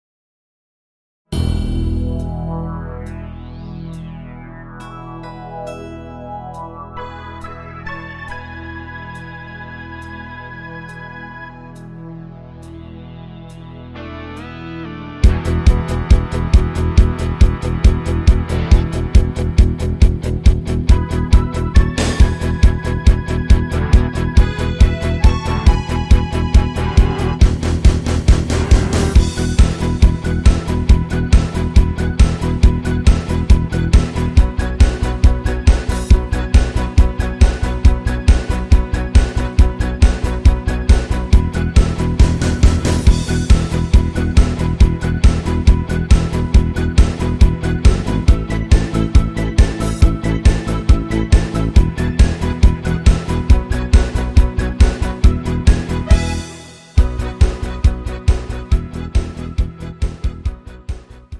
Rock Version